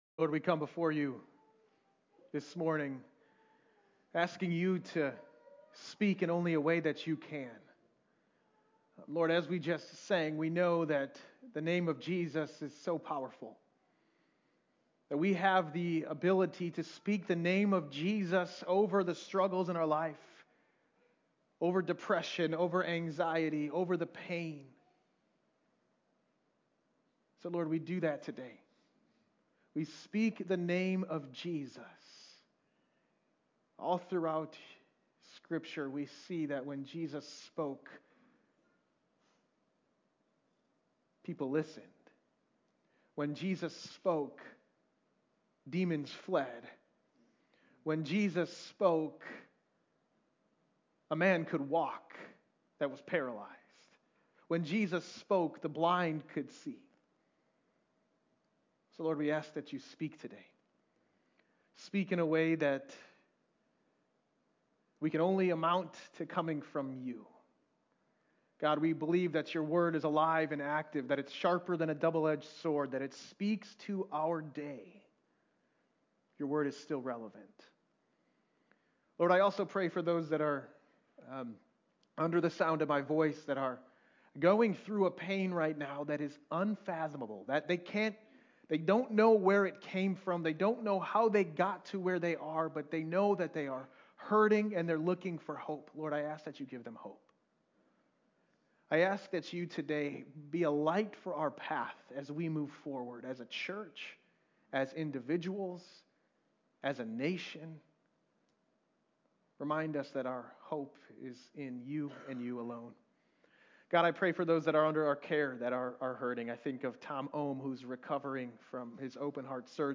Sermons Archive - Hope Community Church Of Lowell